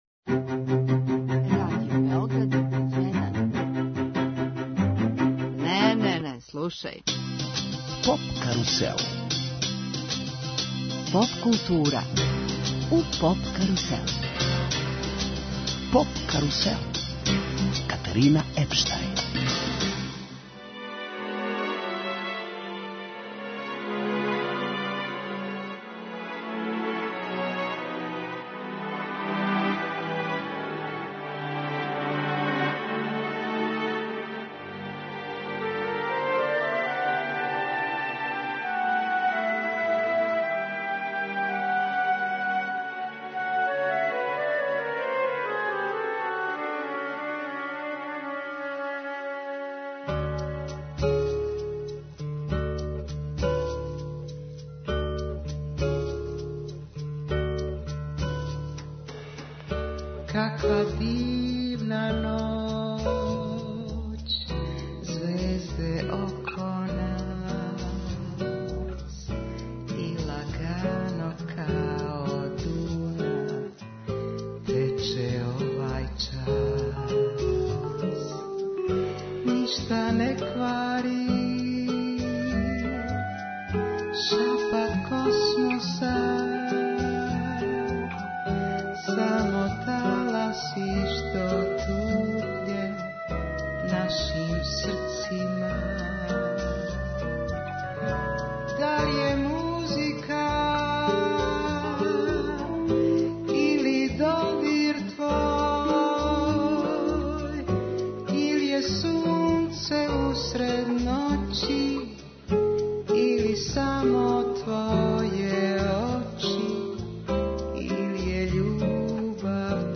Гошћа емисије је прослављена певачица Бети Ђорђевић, овогодишња добитница награде за животно дело, на фестивалу Нишвил.